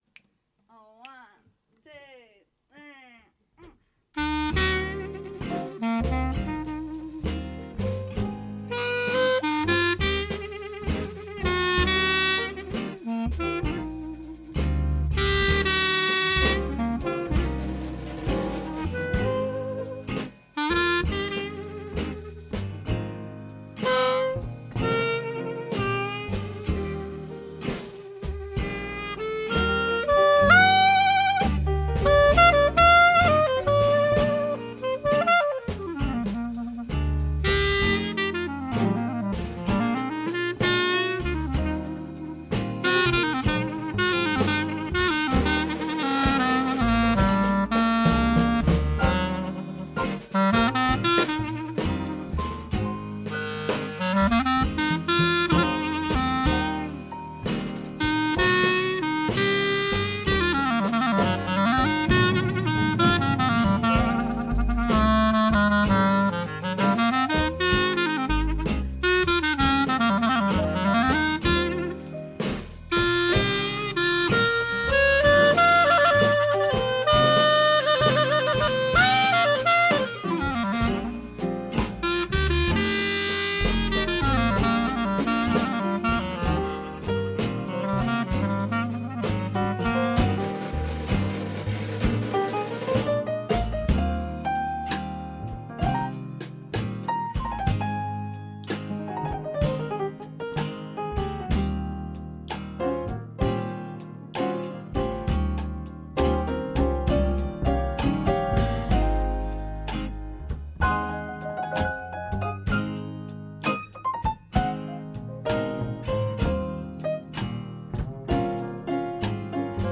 Prohibition Rag Band | Clarinet-led Vintage Jazz Band
• Covering 1920s-1950s hot jazz, early blues, swing and RnB, as well as jazz arrangements of modern songs
The full-bodied sound, high energy and soulful playing, coupled with the band's vintage aesthetic, creates an immersive and authentic jazz experience, perfect for any prohibition-era themed Gatsby party.
Clarinet, Drums, Guitar, Keys
prohibition-rag-band-kansas-city-man-blues.mp3